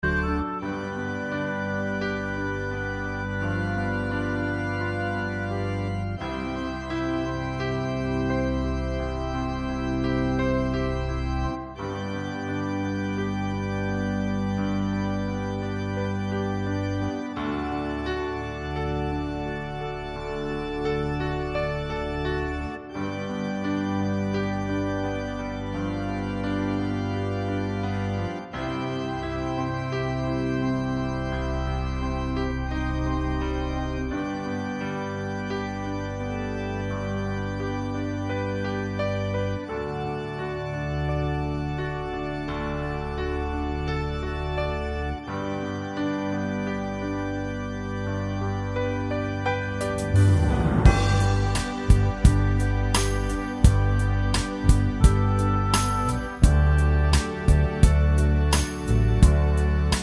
no Backing Vocals Crooners 4:55 Buy £1.50